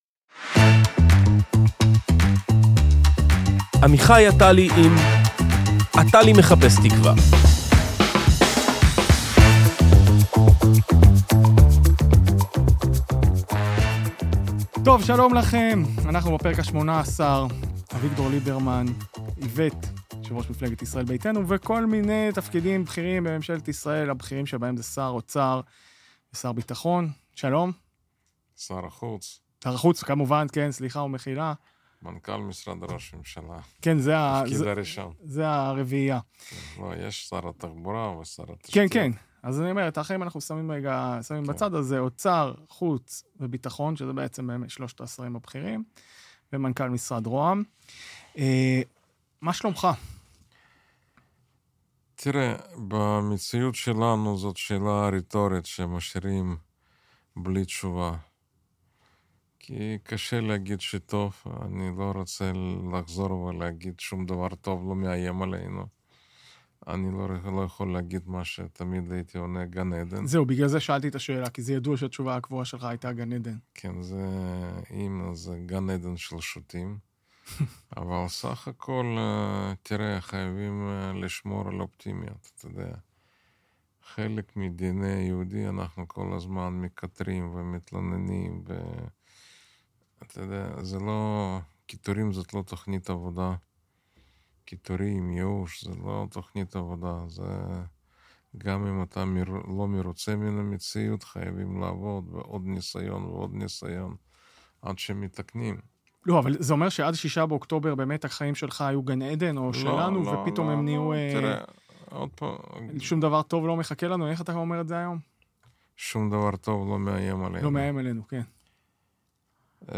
שיחה על חקלאות, מורשת, צבא וציונות